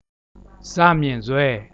Palatal
Za-myin-zwei {Za.mying:hswè:} <))